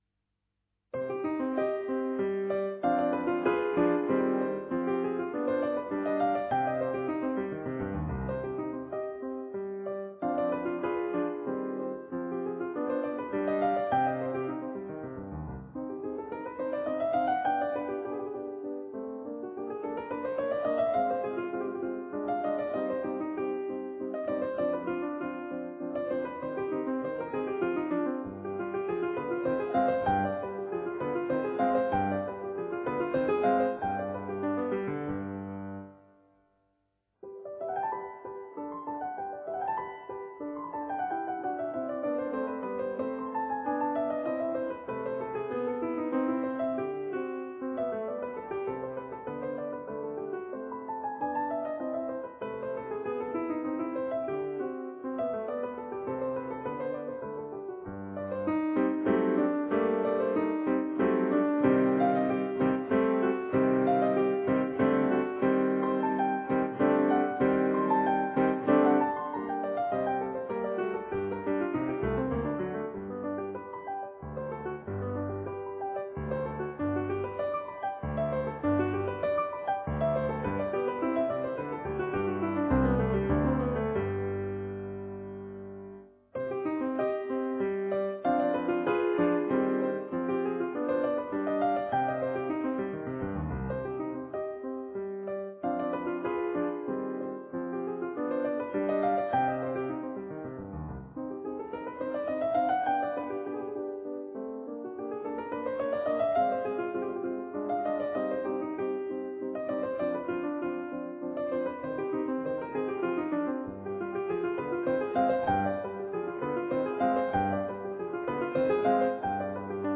Domenico Scarlatti - Le sonate eseguite al pianoforte - mp3
Queste registrazioni, che fanno parte di una quasi-integrale delle Sonate di Domenico Scarlatti, sono state da me realizzate nei primi mesi del 2003 con un pianoforte digitale Yamaha.